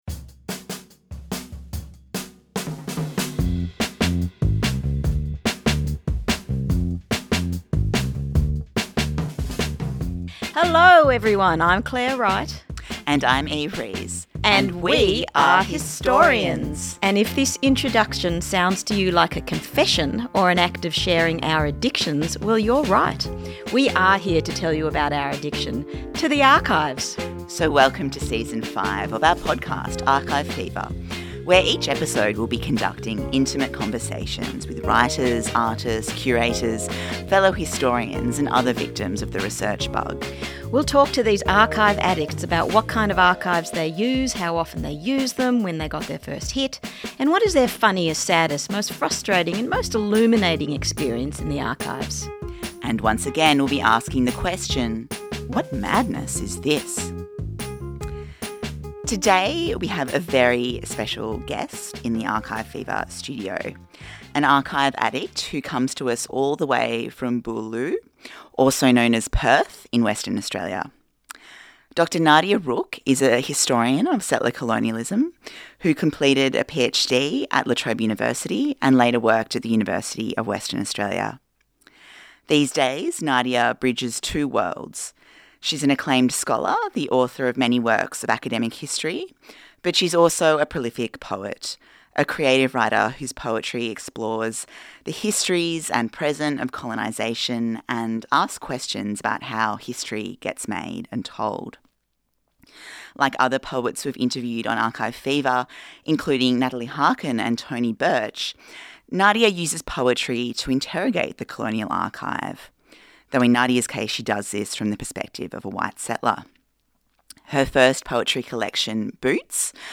In a conversation that tackles the limitations of the history discipline